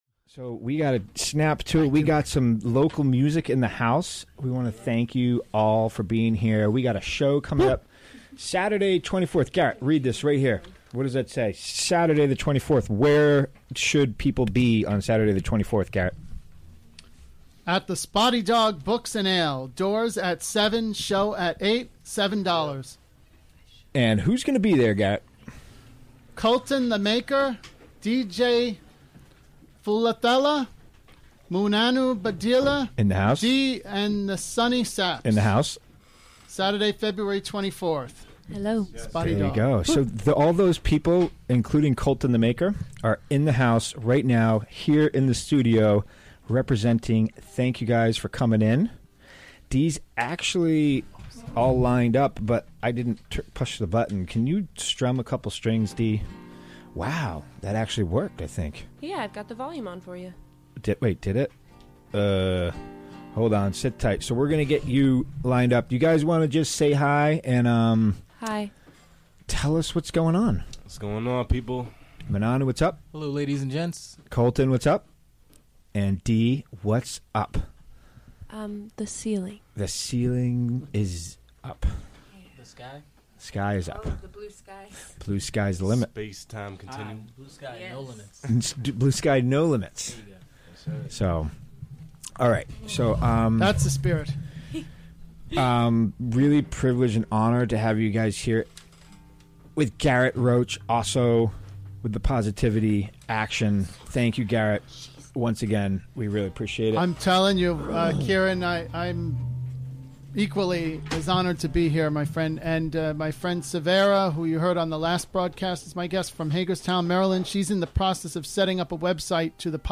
Recorded during the WGXC Afternoon Show on Monday, February 12, 2018.